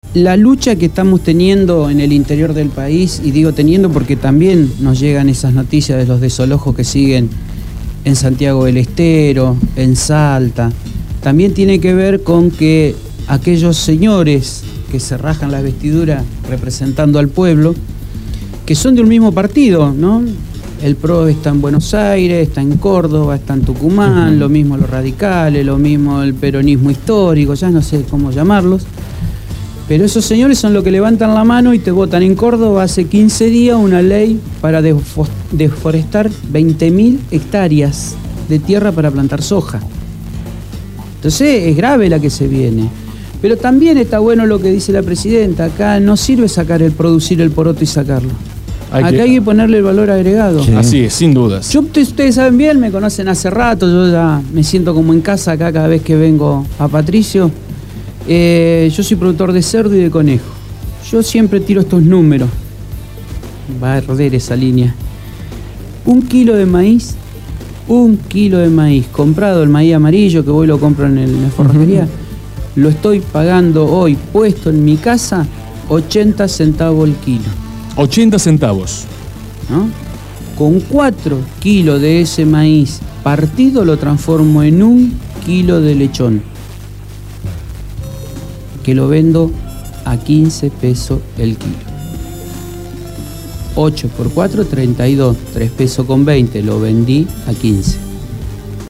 estuvo en el estudio de la Gráfica.
Entrevistado